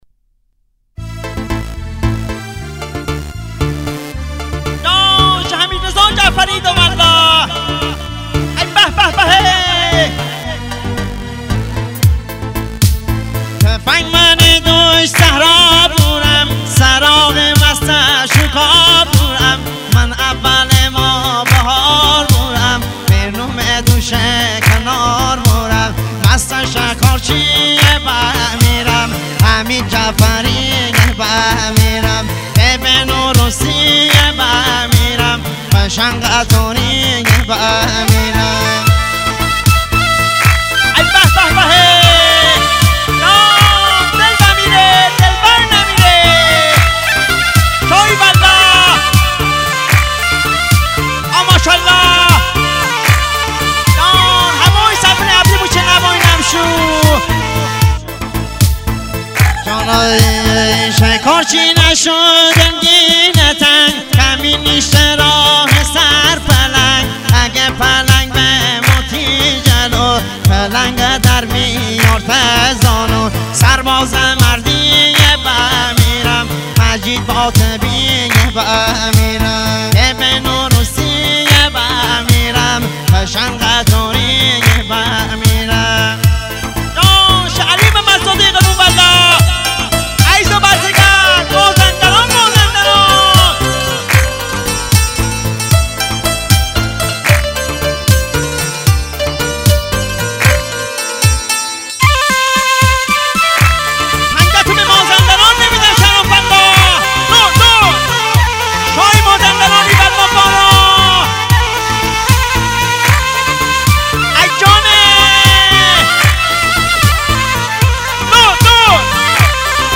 ریتمیک ( تکدست )